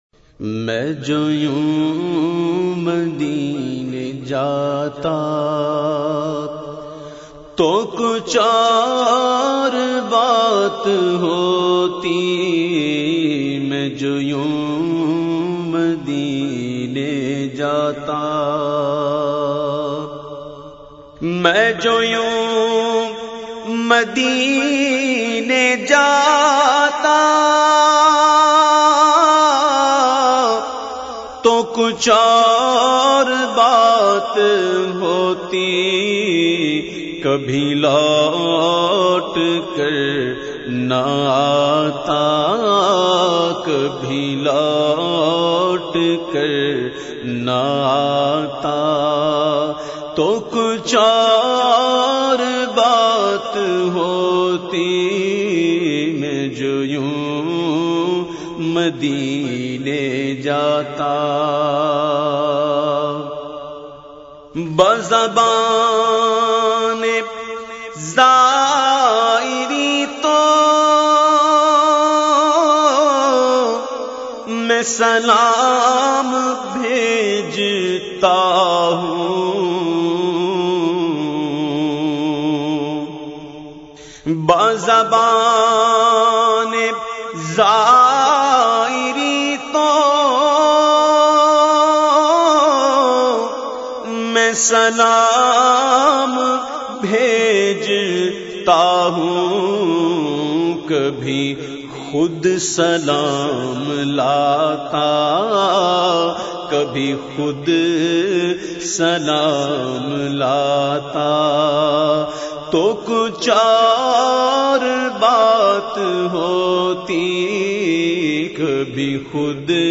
The Naat Sharif main jo youn madine jata recited by famous Naat Khawan of Pakistan Owais Raza Qadri.